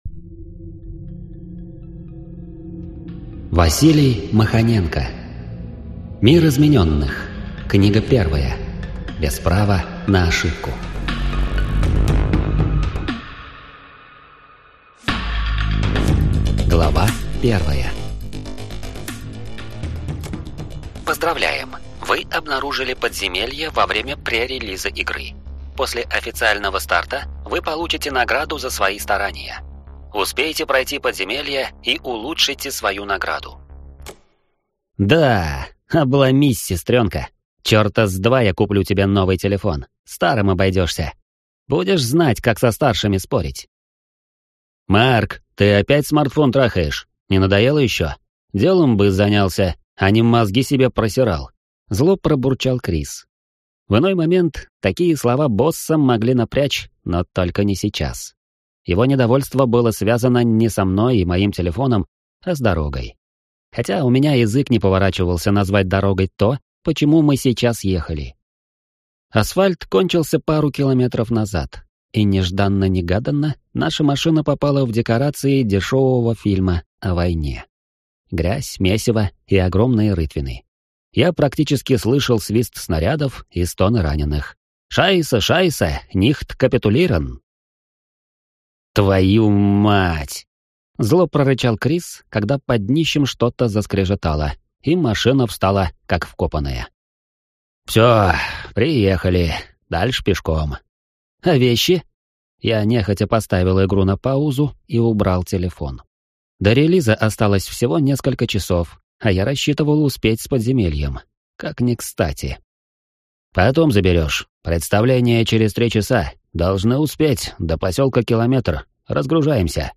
Аудиокнига Мир измененных. Книга 1. Без права на ошибку | Библиотека аудиокниг